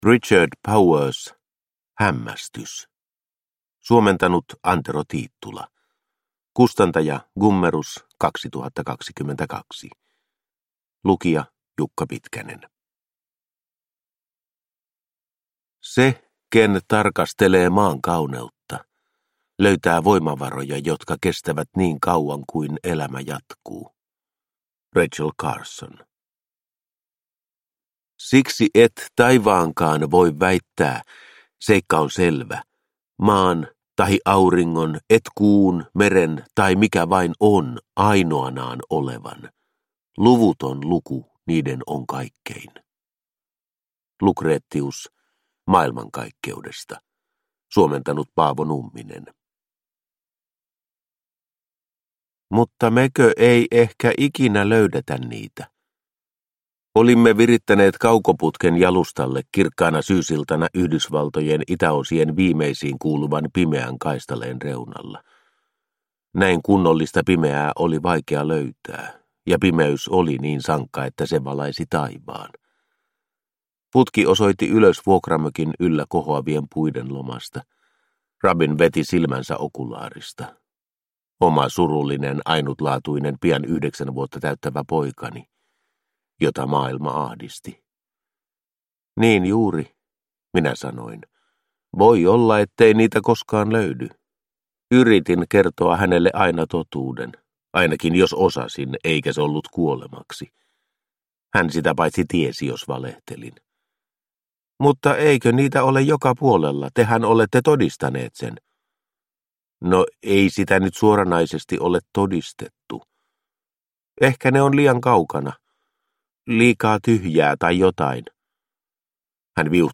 Hämmästys – Ljudbok – Laddas ner